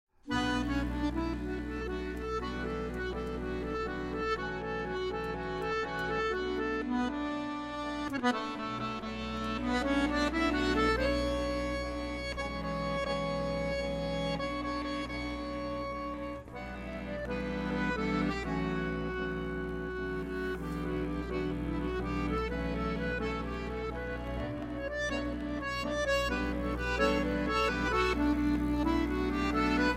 written for two accordions as well as accordion ensemble
The album is melodic as melody is the root of the story.
string bass
bass accordion
drums